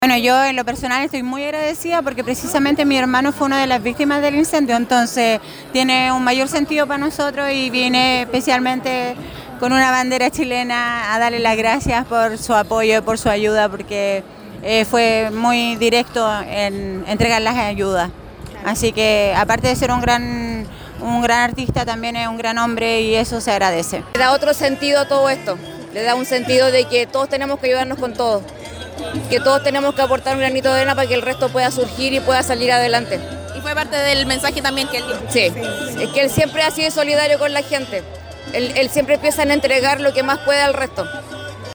Siguió la música y el bailoteo por montones, cuando, por segunda vez, frenó su presentación, esta vez para referirse a los devastadores incendios forestales que afectaron a la región del Bío Bío durante enero, instancia donde valoró presentarse en Concepción y envió apoyo a las comunidades afectadas.
El público respondió con aplausos y muestras de cariño, agradeciendo también el apoyo monetario que recientemente brindó a la región y la energía que el cantante entregó durante la jornada.